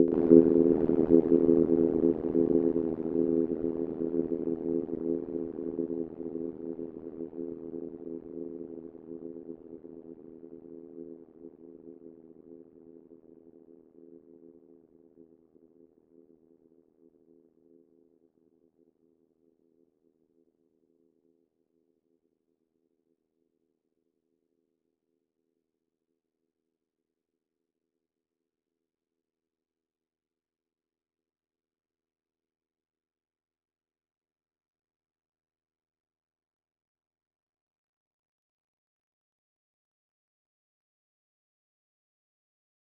cowbell.wav